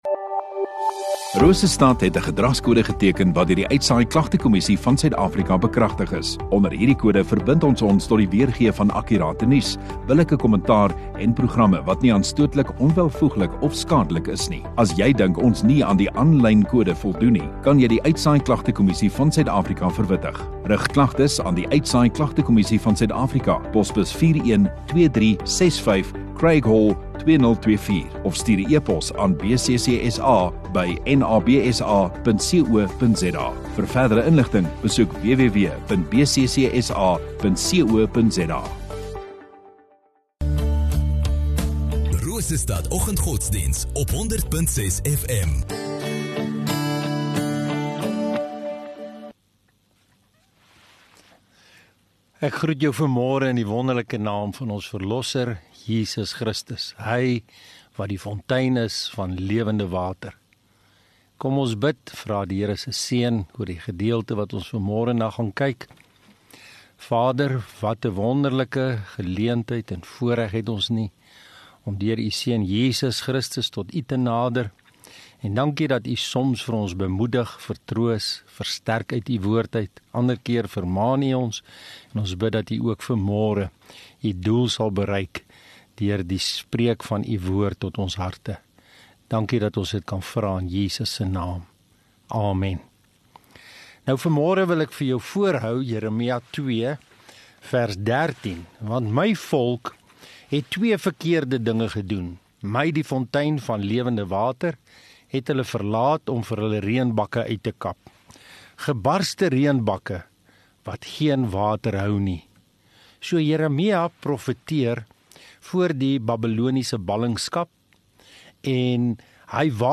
Woensdag Oggenddiens